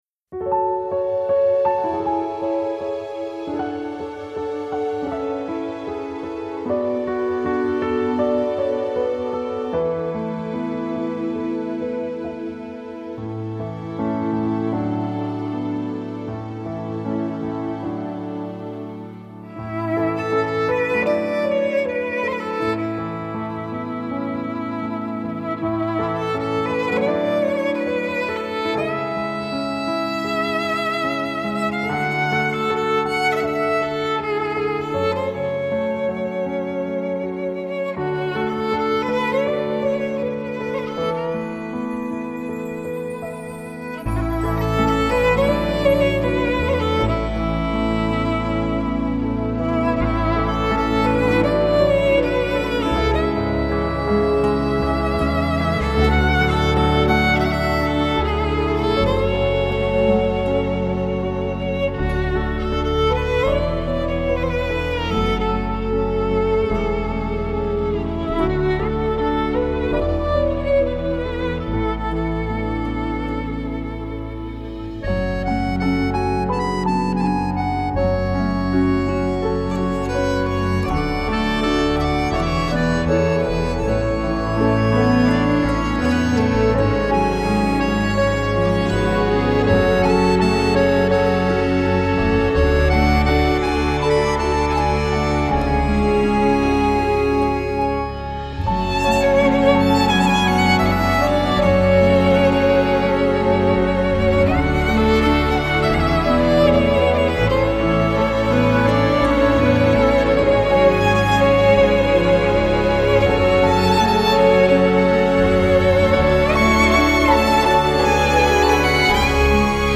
小提琴
钢琴、键盘、口哨
11首全新创作歌曲, 以纯音乐为主